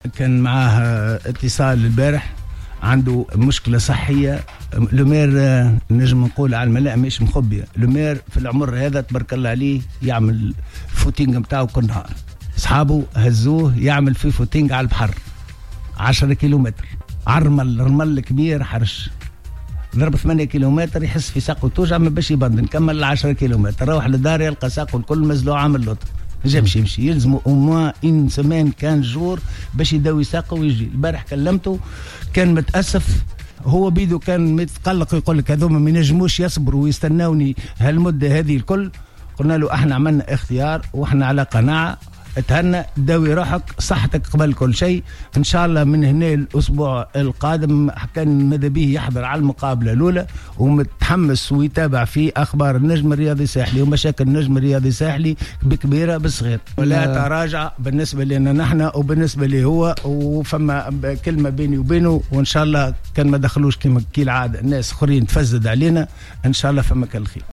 أكد رئيس النجم الرياضي الساحلي، رضا شرف الدين، في تصريح للجوهرة أف أم، اليوم الأربعاء، أن المدرب الفرنسي روجي لومار تعرض لإصابة على مستوى الرجل أثناء ممارسته للرياضة، عطلت قدومه إلى سوسة للإشراف على تدريب فريق جوهرة الساحل.